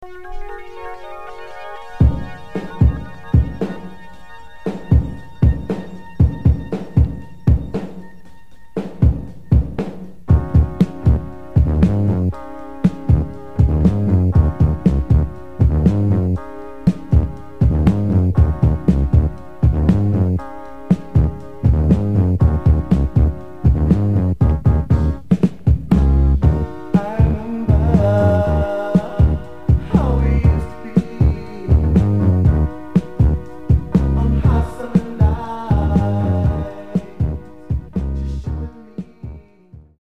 Genre: Soul/Funk